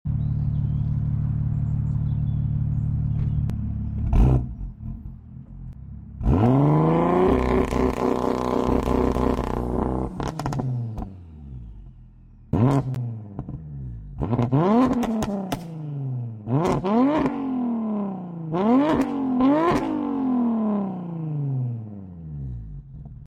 Single Turbo Launch Control x sound effects free download
Single Turbo Launch Control x Anti Lag Sounds